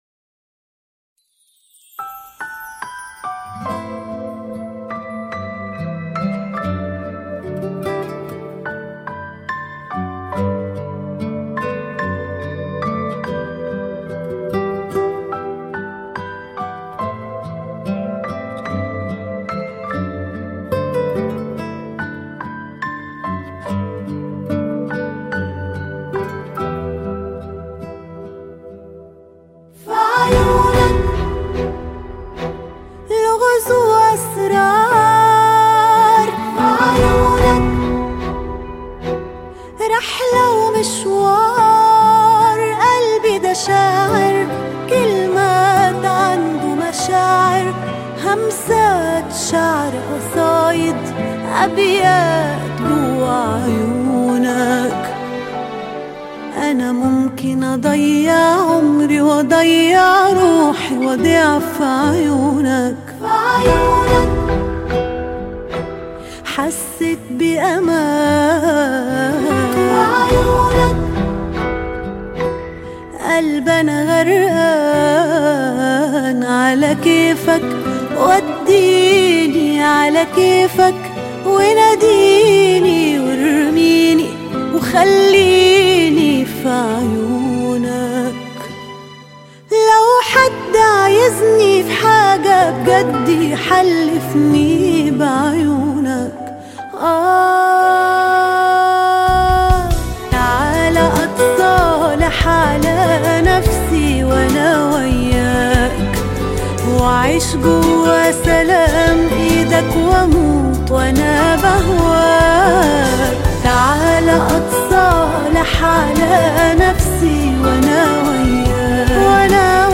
اغنية عربية